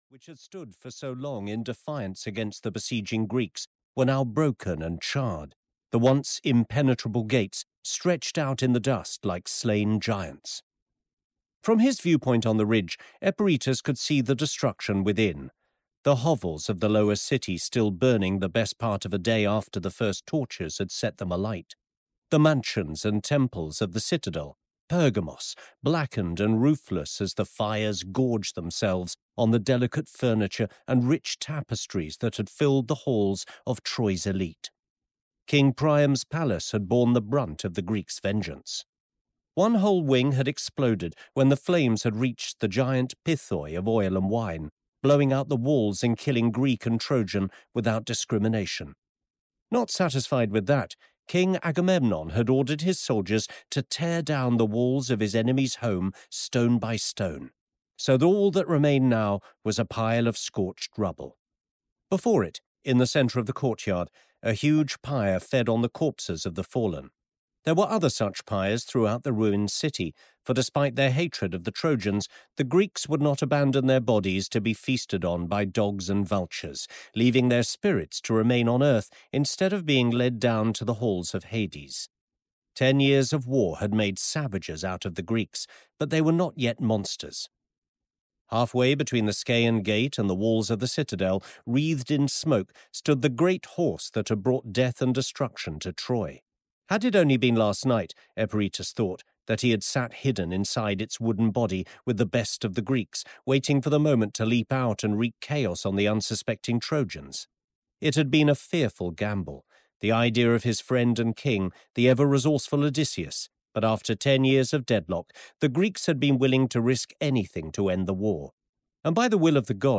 The Voyage of Odysseus (EN) audiokniha
Ukázka z knihy